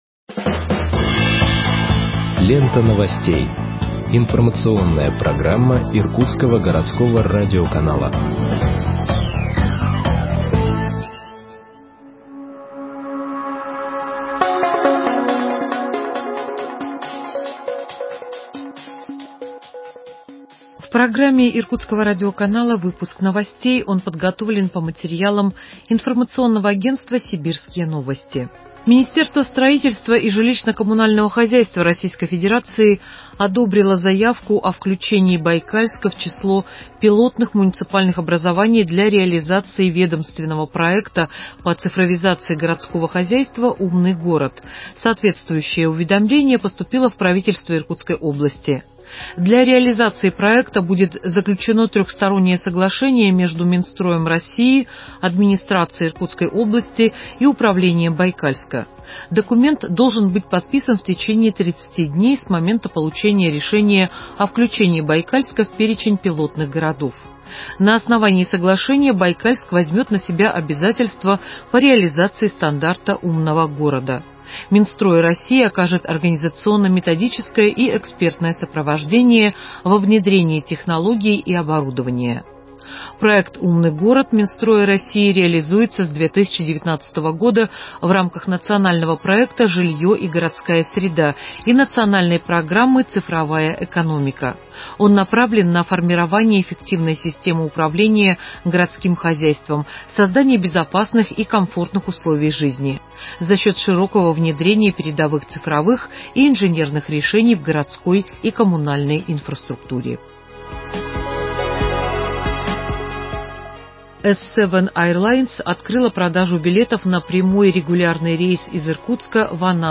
Выпуск новостей в подкастах газеты Иркутск от 09.06.2021 № 1